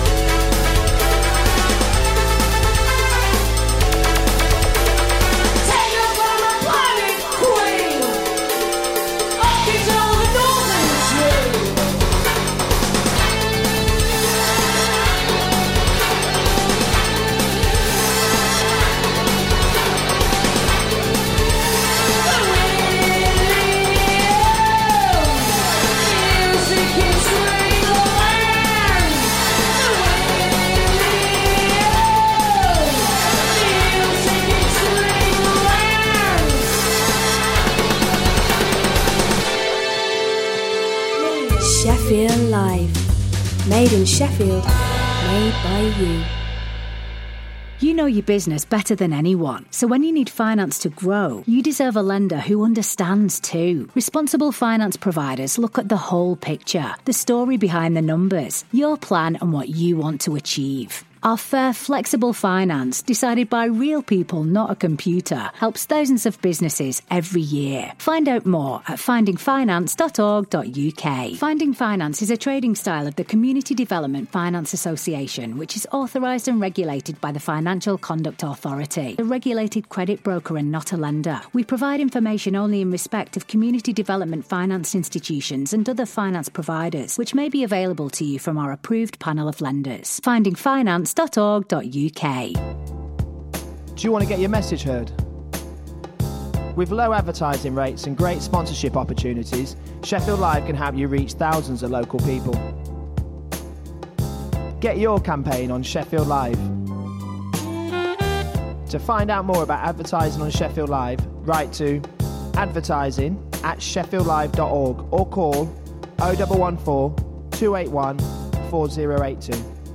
The very best new upfront music ahead of release dates & classic old tunes ranging round dub, chill, breaks, jungle, dnb, techno & anything else